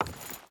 Wood Chain Walk 4.ogg